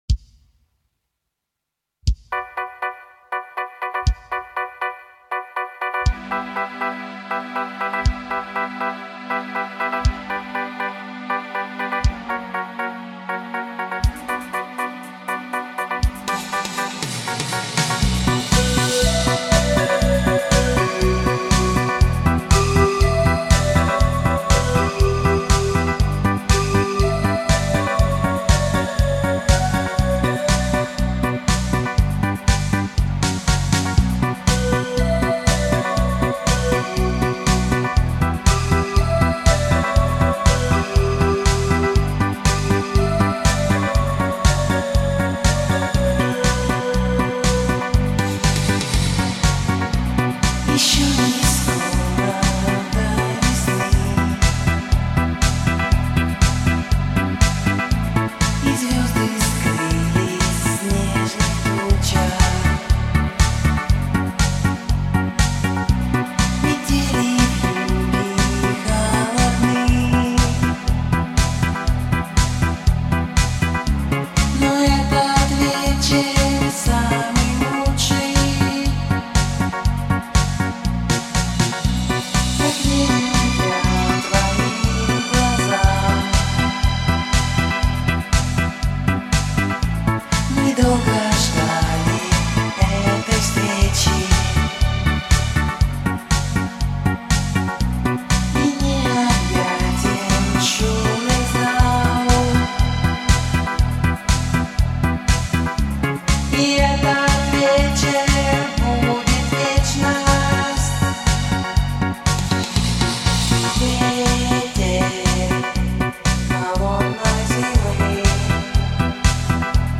это романтичный и меланхоличный трек в жанре поп.